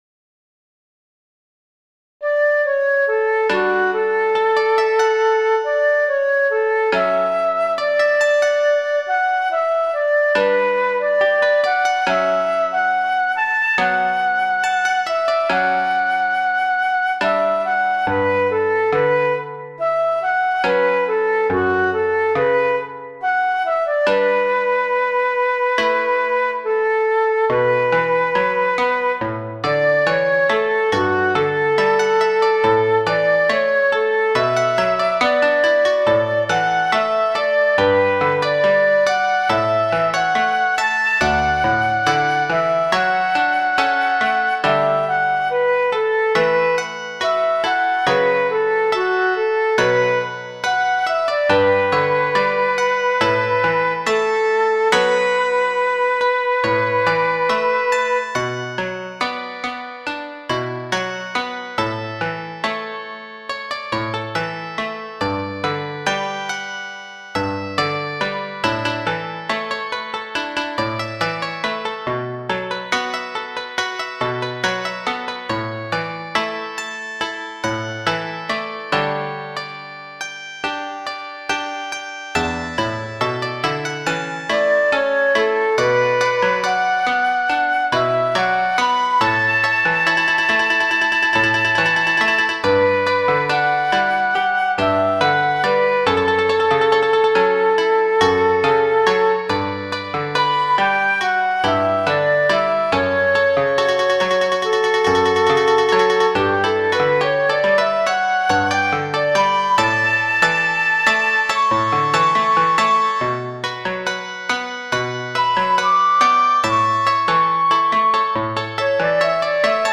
【編成】箏２・十七絃・尺八（一尺六寸） 作曲途中のイメージは「郷愁」でした。
しかし作り終え、通して聴いてみると、寒さと、その中の穏やかな明るさを感じました。